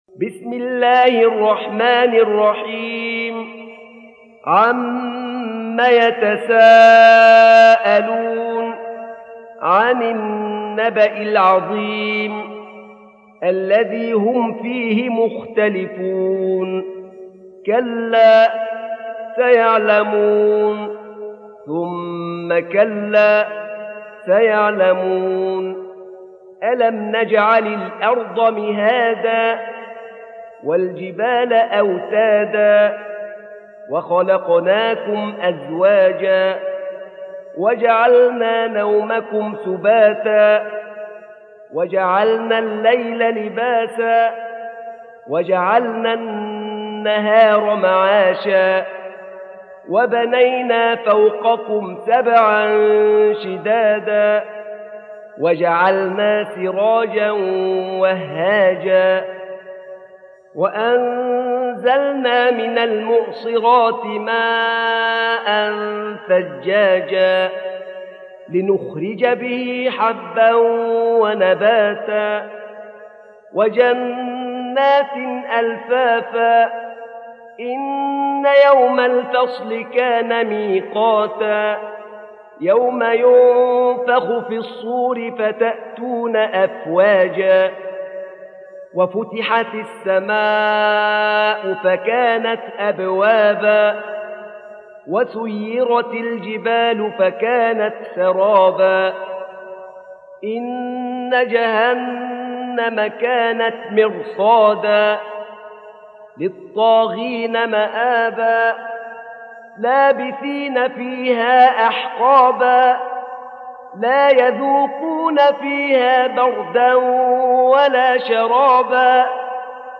سورة النبأ | القارئ أحمد نعينع